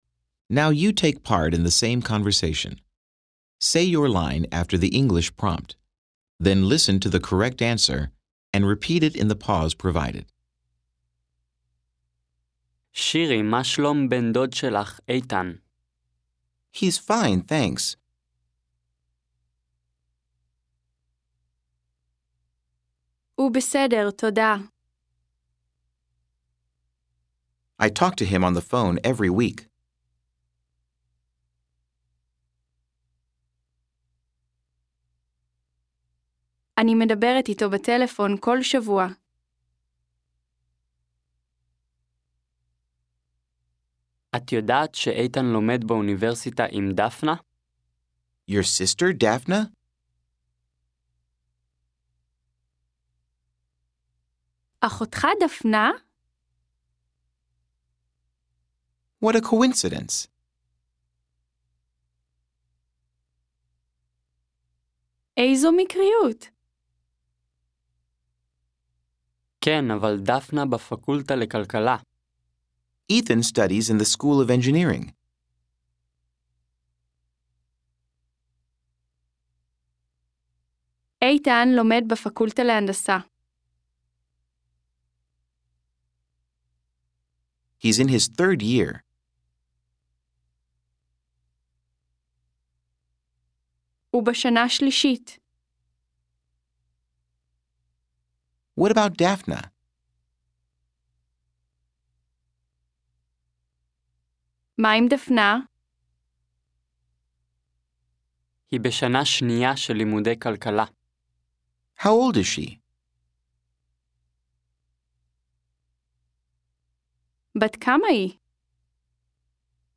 10 Dialogue 1c.mp3